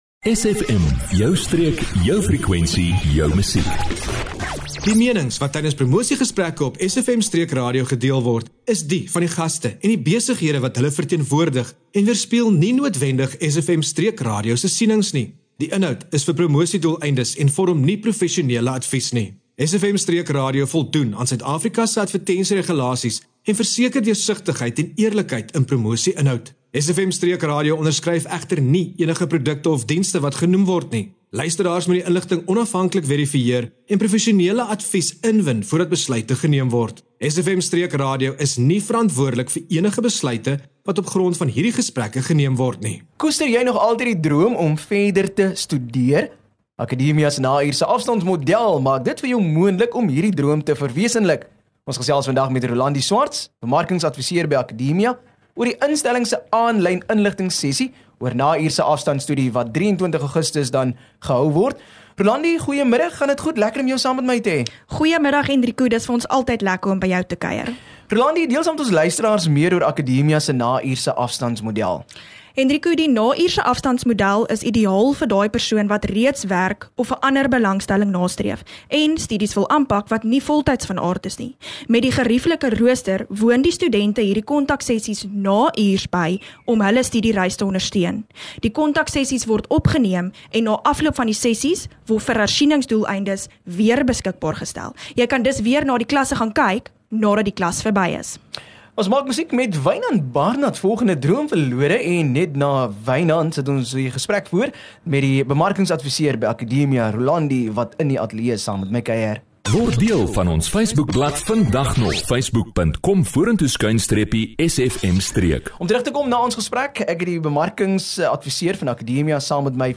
SFM Allegaartjie van onderhoude